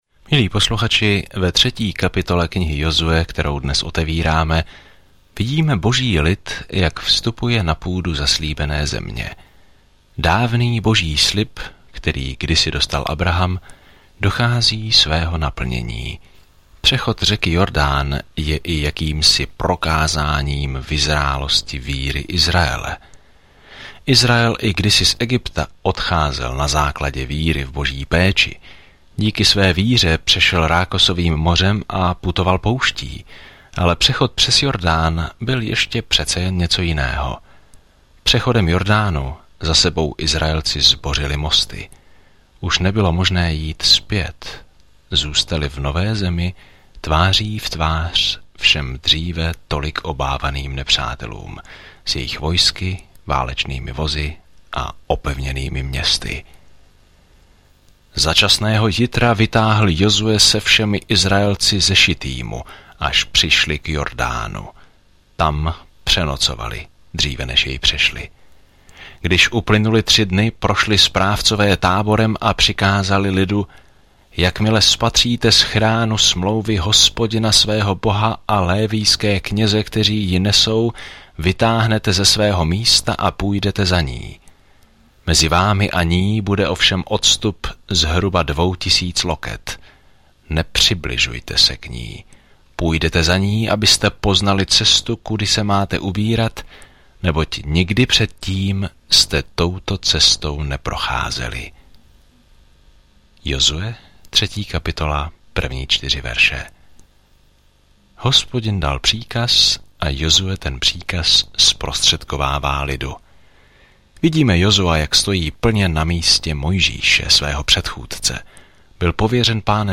Písmo Jozue 3 Jozue 4 Jozue 5:1 Den 2 Začít tento plán Den 4 O tomto plánu Nazvěme knihu Jozue „Exodus: Část druhá“, když nová generace Božího lidu zabírá zemi, kterou jim slíbil. Denně procházejte Jozuem, zatímco posloucháte audiostudii a čtete vybrané verše z Božího slova.